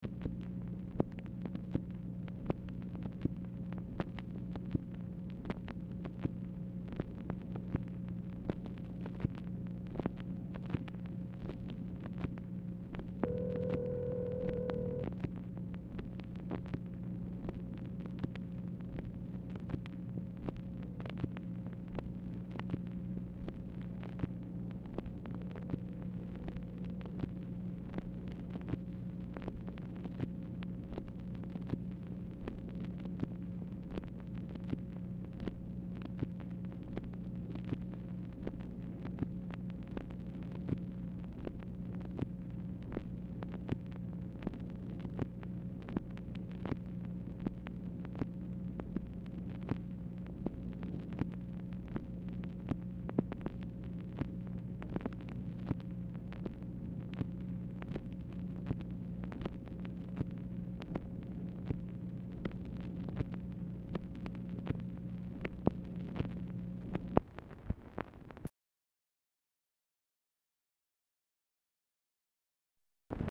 Telephone conversation # 13420, sound recording, MACHINE NOISE, 9/26/1968, time unknown | Discover LBJ
Title Telephone conversation # 13420, sound recording, MACHINE NOISE, 9/26/1968, time unknown Archivist General Note "B.1"; "SEN.
Dictation belt